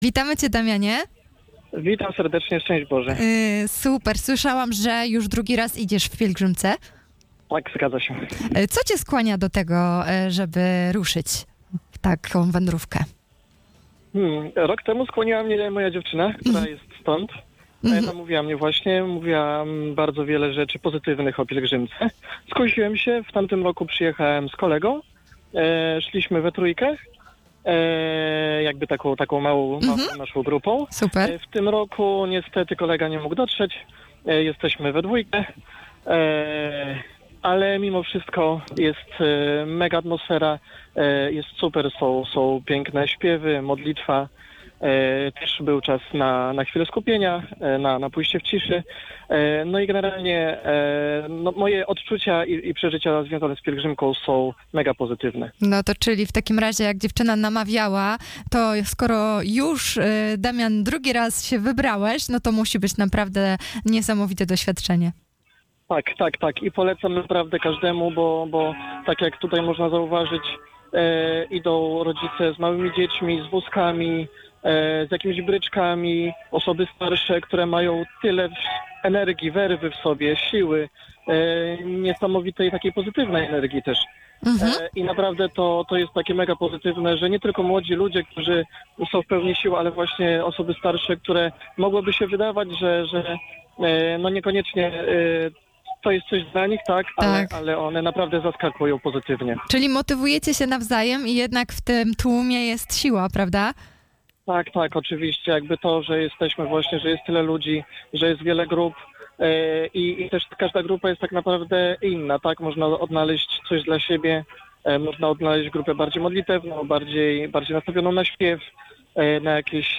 rozmowa-na-antenie-PILEGRZYMKA-2.mp3